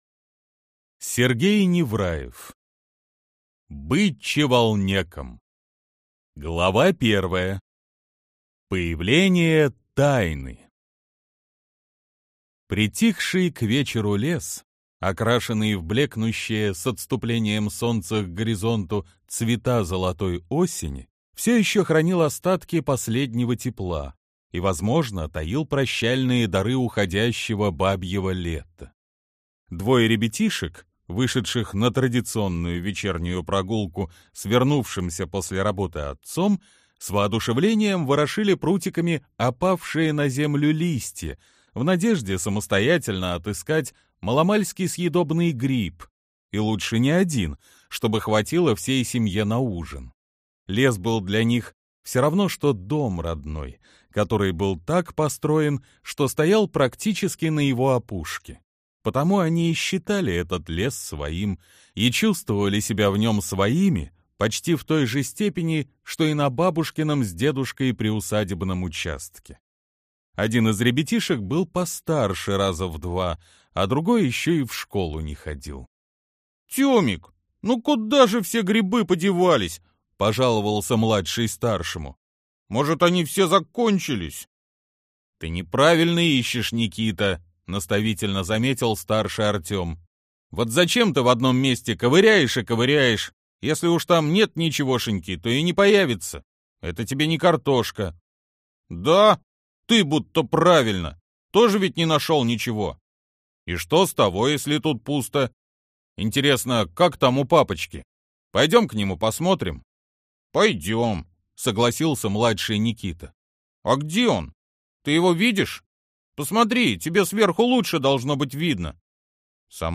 Аудиокнига Быть чеволнеком | Библиотека аудиокниг